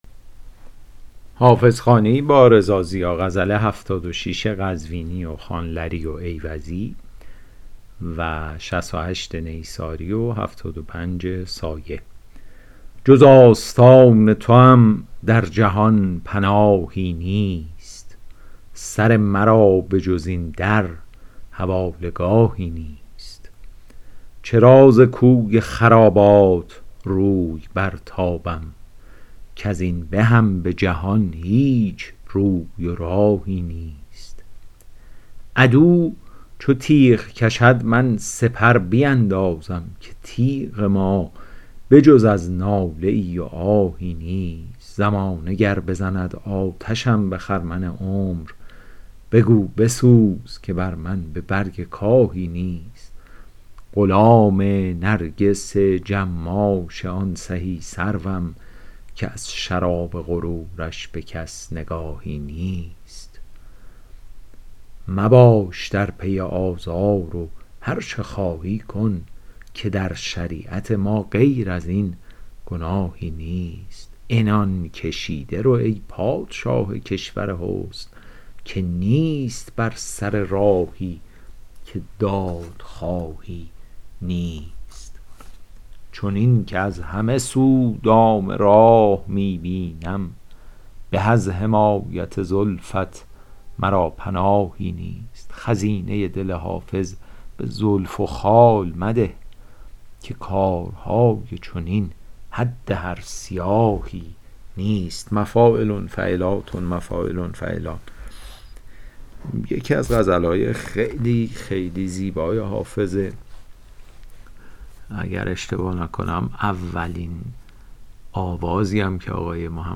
شرح صوتی غزل شمارهٔ ۷۶